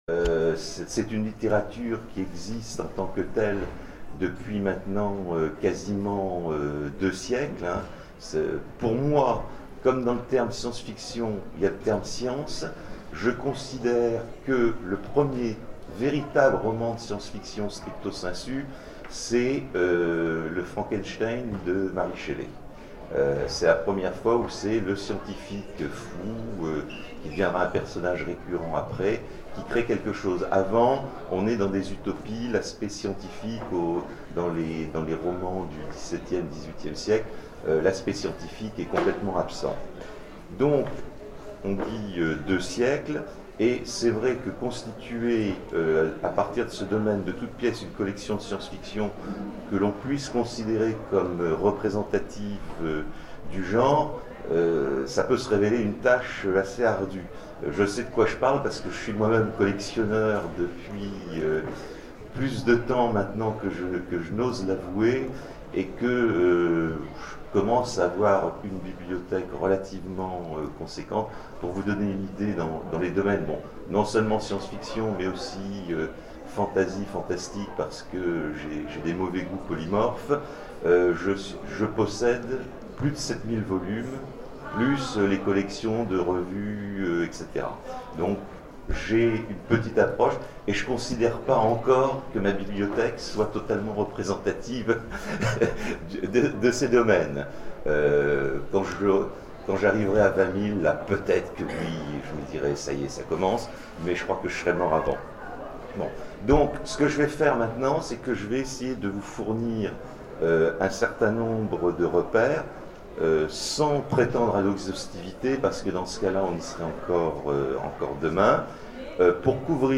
Zone Franche 2012 : Conférence Constituer une collection de science-fiction